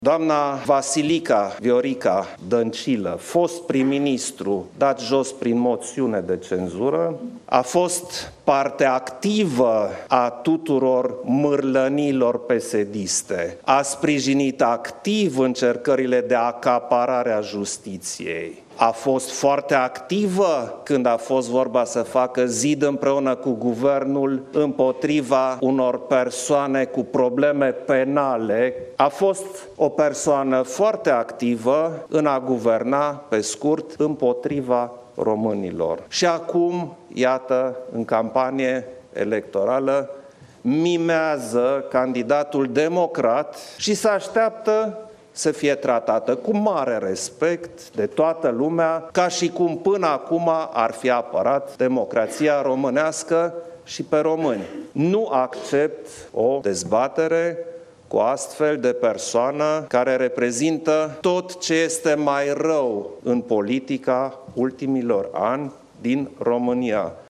În această seară, preşedintele Klaus Iohannis a susţinut o conferinţă de presă, în timpul căreia s-a referit la primul tur al alegerilor prezidenţiale.